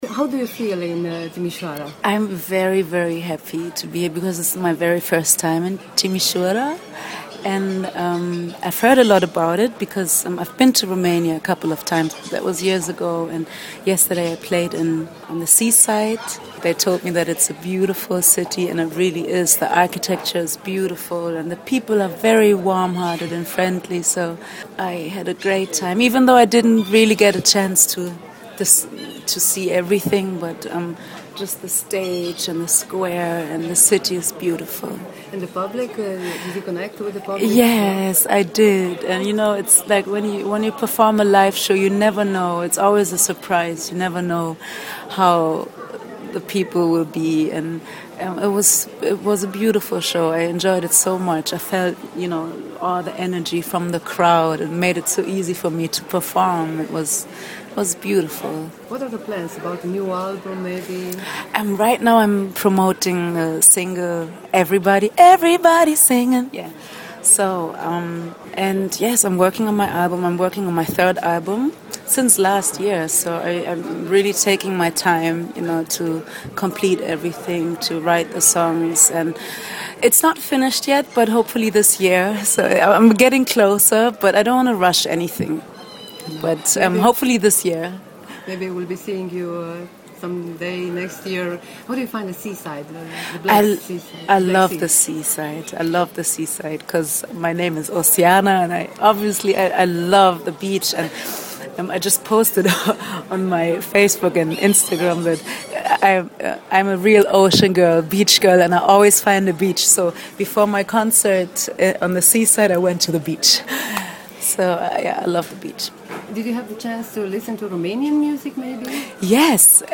Românii au un simț muzical extraordinar, mai spune Oceana în interviul acordat pentru Radio Timișoara.